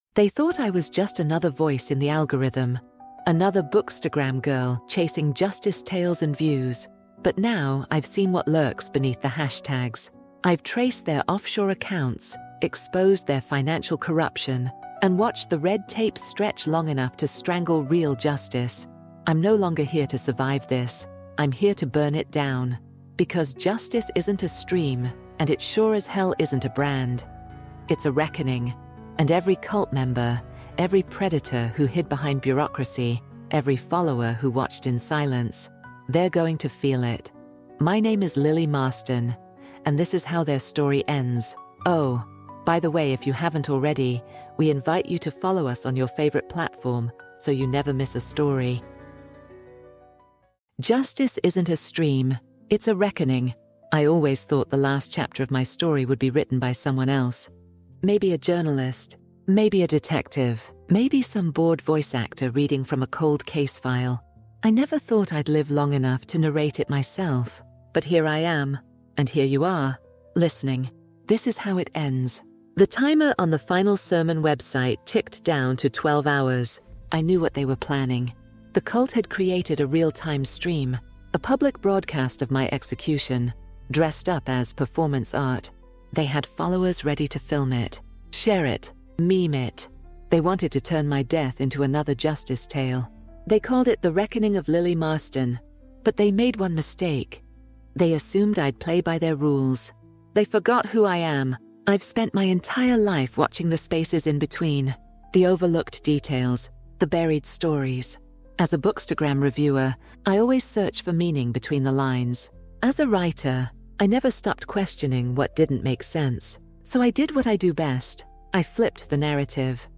True Crime | Shadows of Justice EP5 | Justice Isn’t a Stream It’s a Reckoning | Audiobook